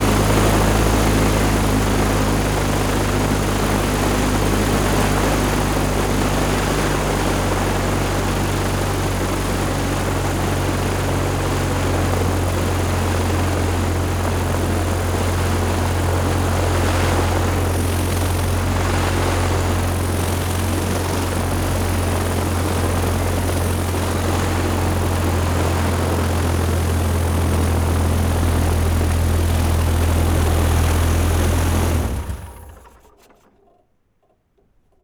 propeller_plane.wav